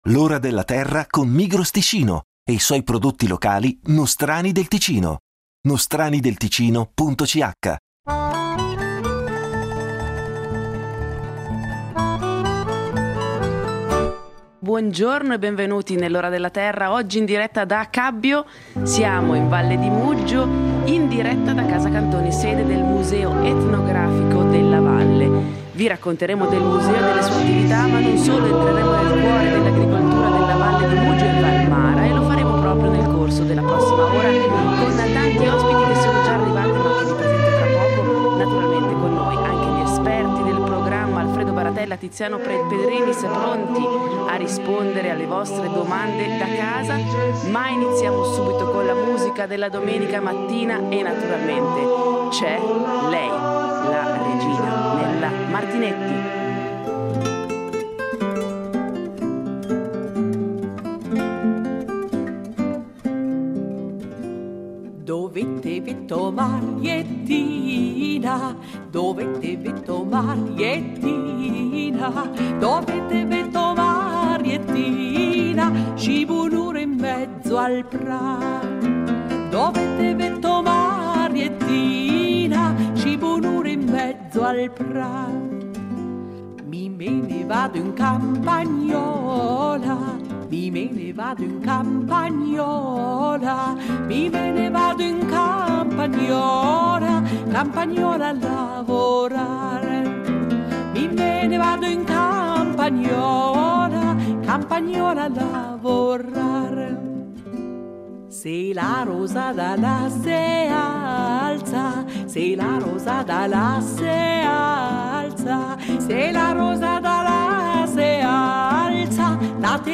In diretta da Casa Cantoni, sede del Museo Etnografico della Valle di Muggio vi racconteremo dell’Associazione per i Prodotti della Valle di Muggio e Val Mara, che identifica i prodotti della regione attraverso un marchio ed è inoltre promotrice del Progetto di Sviluppo Agricolo Regionale per il comprensorio del Monte Generoso. Naturalmente con noi anche gli esperti del programma, pronti a rispondere alle vostre domande da casa.